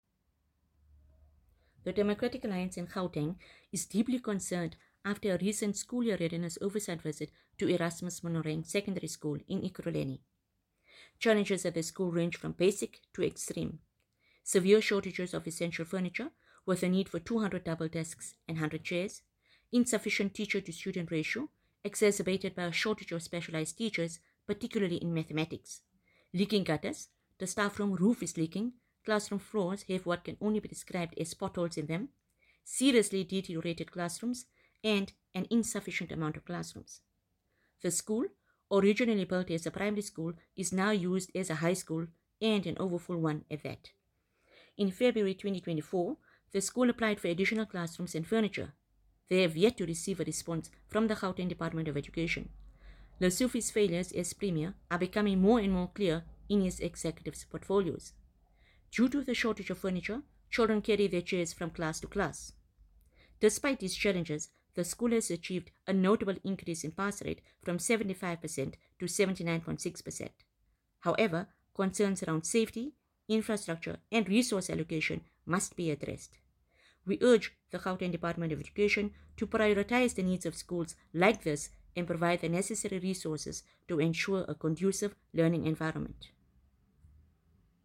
Note to Editors: Please find an English soundbite by Haseena Ismail MP